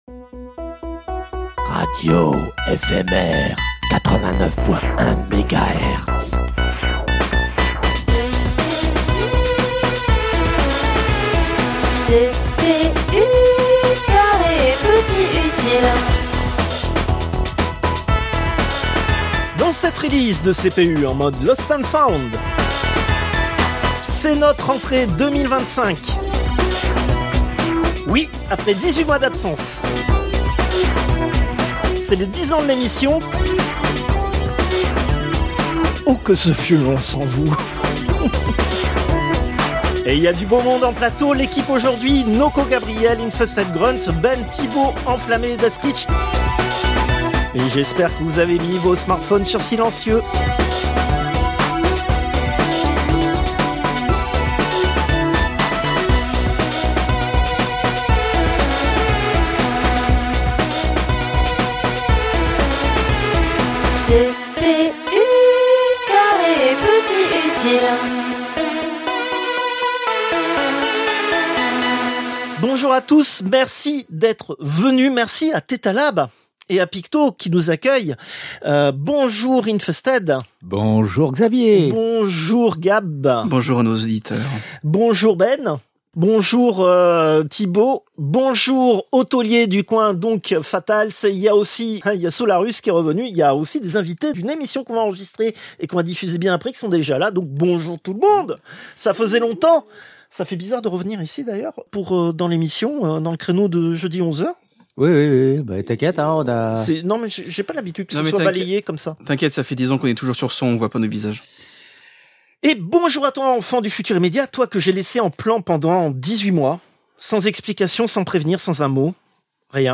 Plateaux enregistrés depuis le Tetalab chez Picto.